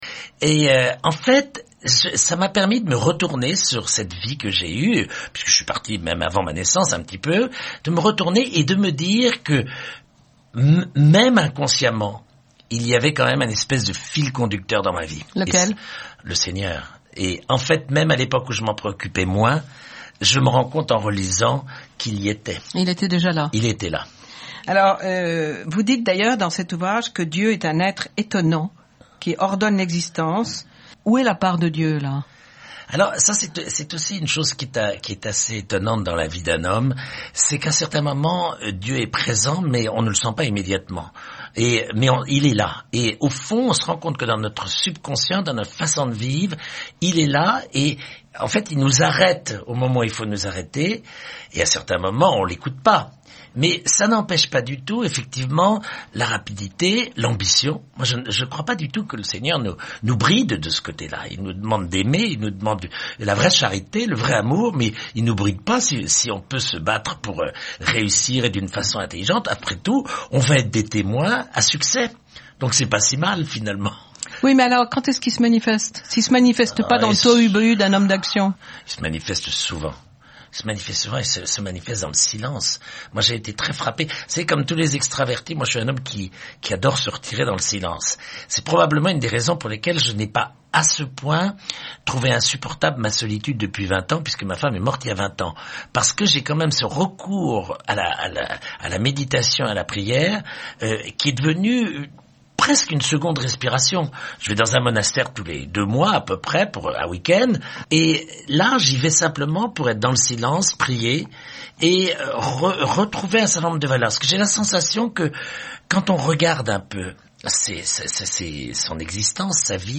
Tout peut être signe et parabole pour ceux que Dieu appelle et qui ne se refusent pas à le chercher - A ECOUTER : extrait d’une interview : en relisant sa vie on perçoit la présence de Dieu.